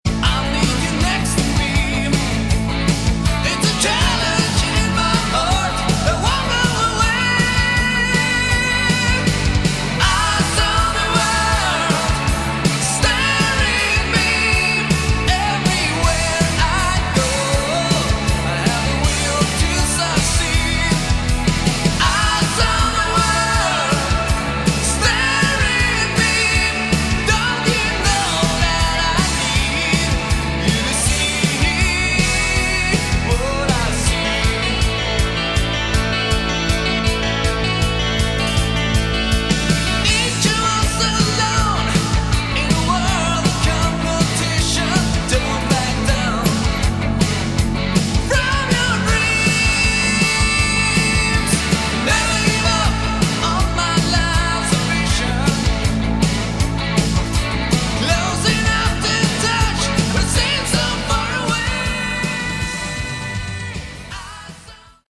subtle Hard Rock/AOR album
genuine and original Melodic hard rock with AOR flavour